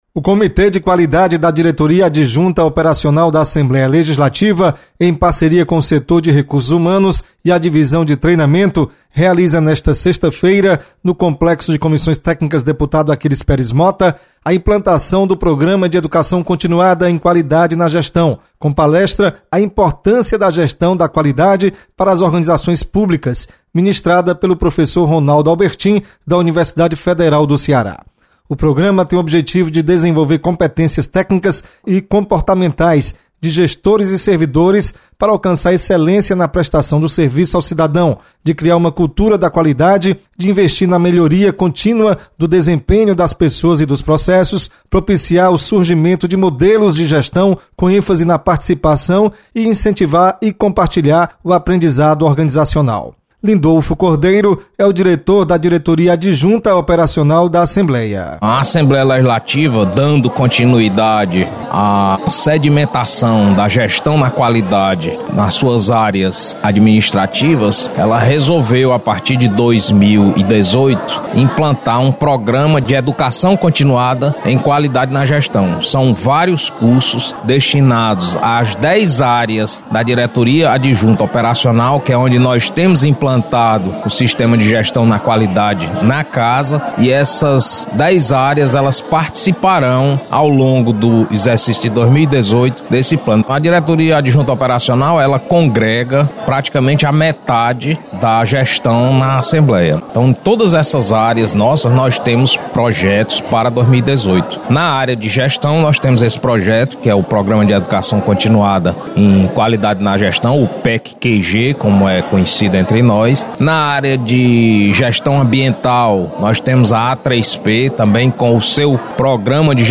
Assembleia Legislativa sedia debate sobre gestão pública. Repórter